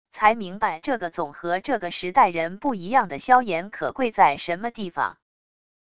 才明白这个总和这个时代人不一样的萧言可贵在什么地方wav音频生成系统WAV Audio Player